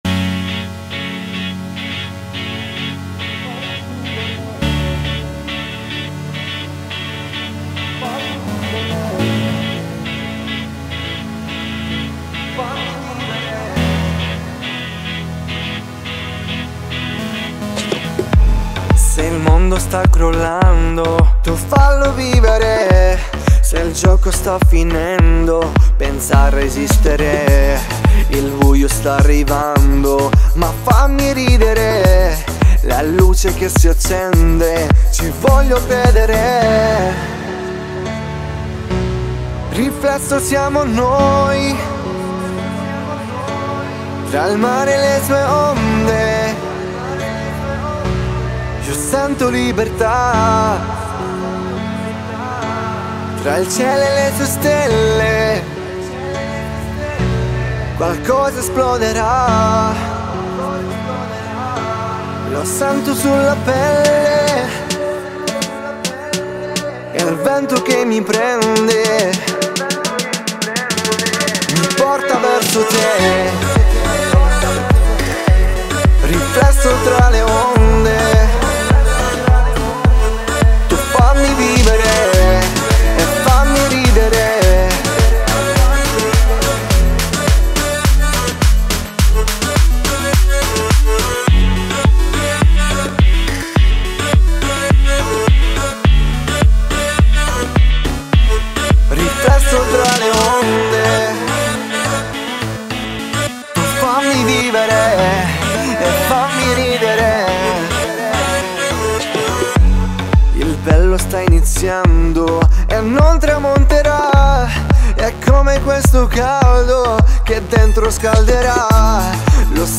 это завораживающая композиция в жанре поп с элементами инди